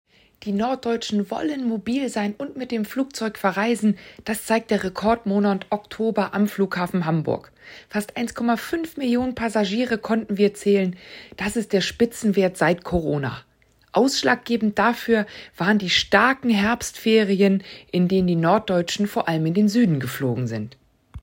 Audio-Statement